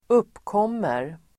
Uttal: [²'up:kåm:er]
uppkommer.mp3